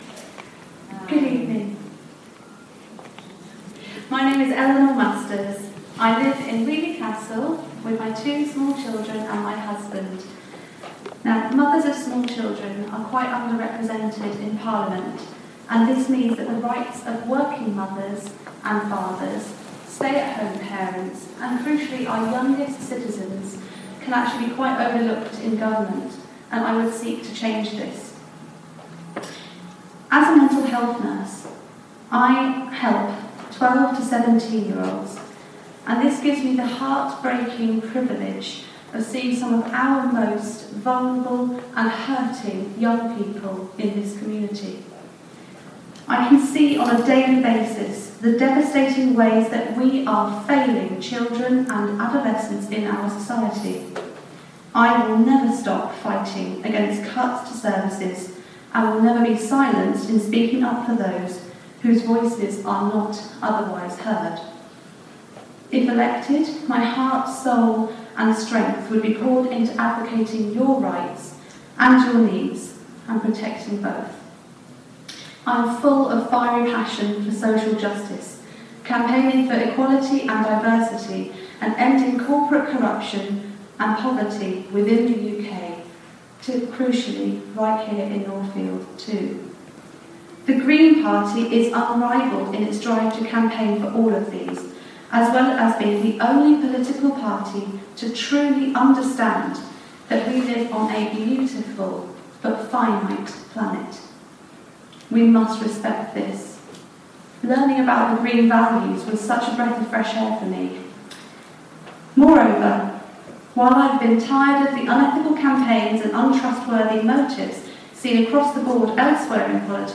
Northfield hustings 02 - opening speeches
The Northfield hustings of 26 May 2017.
Applause has been trimmed.